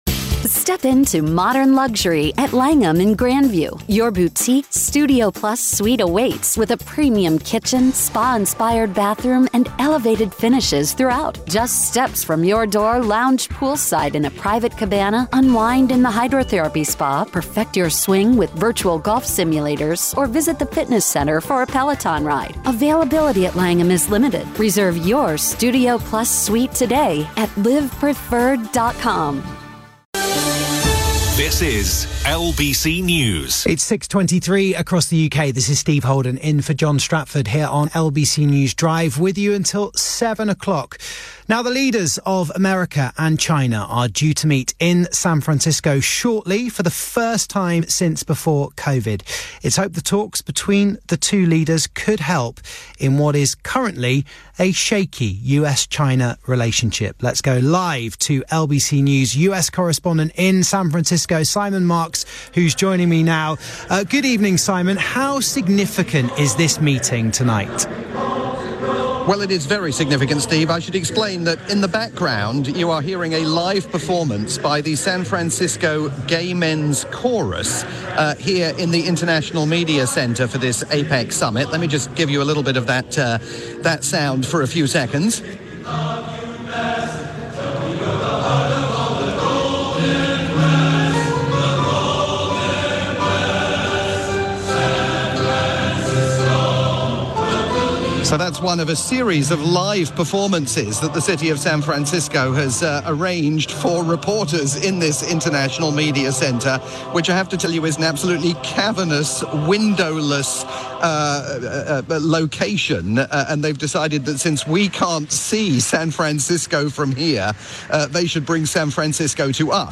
live report for the UK's rolling news station LBC News